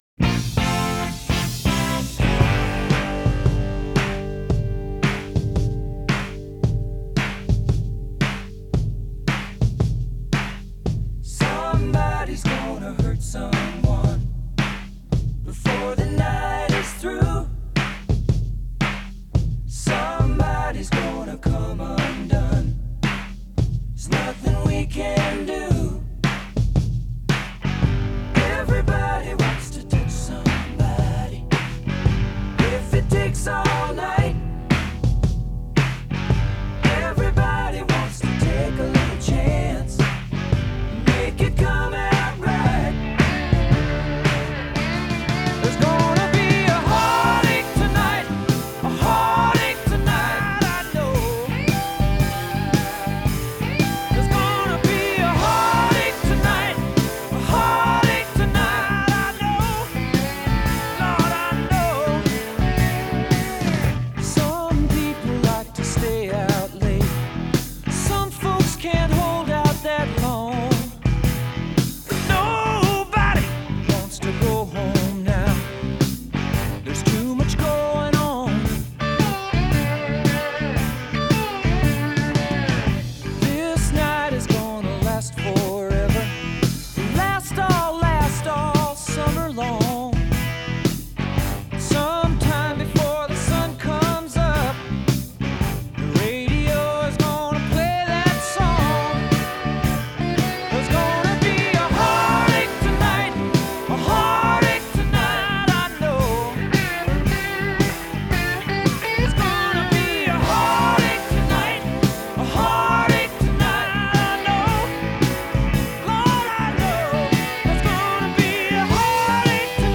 类型：经典摇滚、乡村摇滚
Slide Guitar